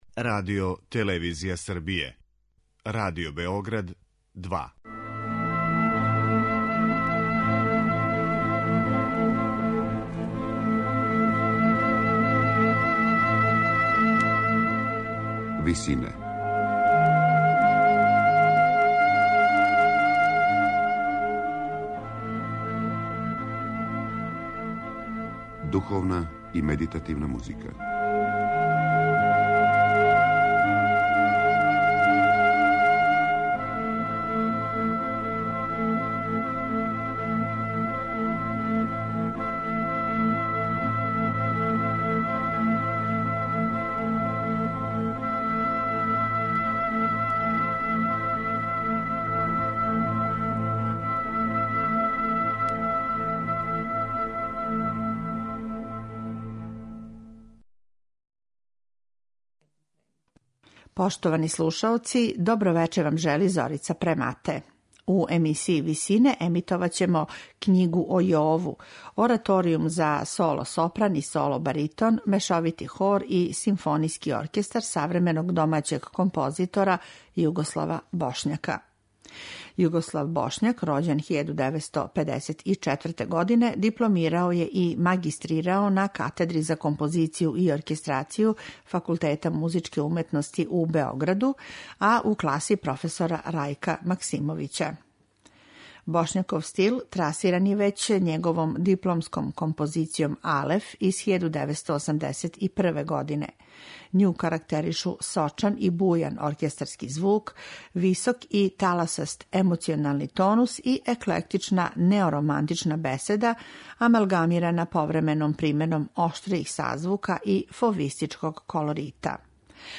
Ораторијум
сопран
баритон